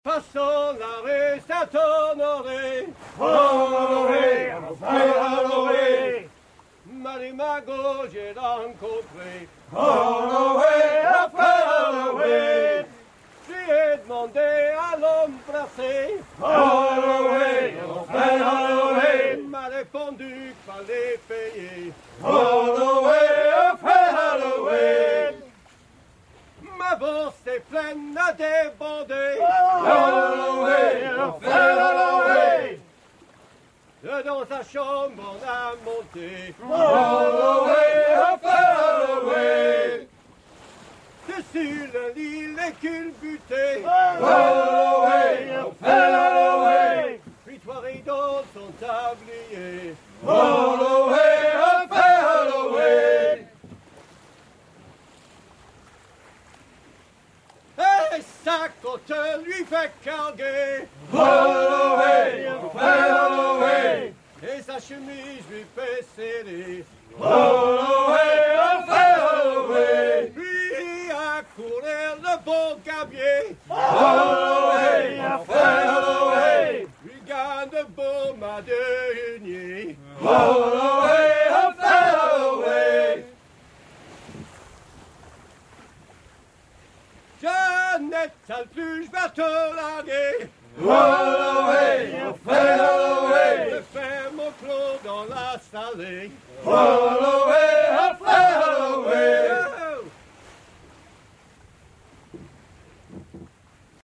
enregistré en mer à bord de la goélette à hunier Jantje.